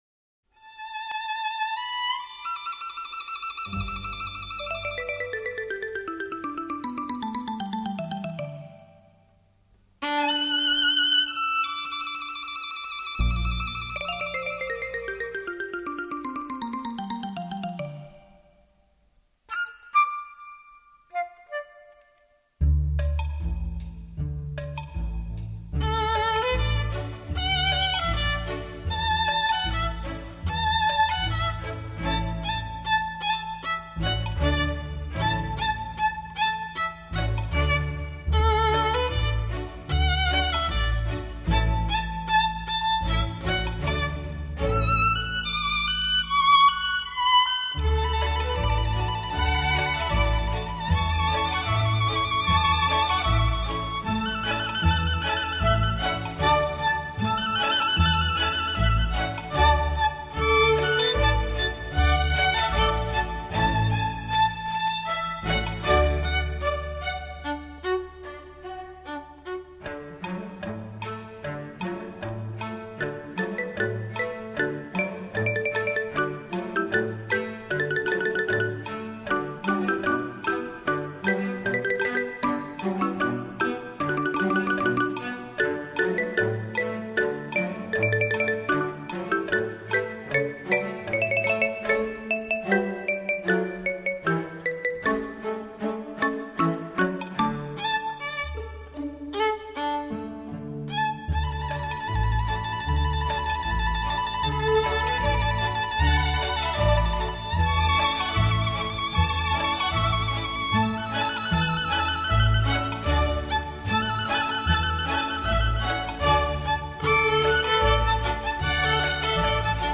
鏗鏘有力、乾淨俐落的節奏搭配銅管及打擊樂器，
使音樂充滿理性及豪邁氣概，